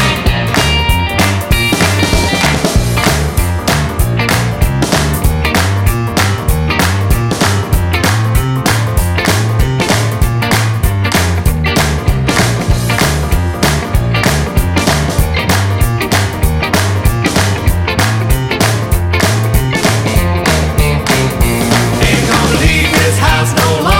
Two Semitones Down Pop (1980s) 3:00 Buy £1.50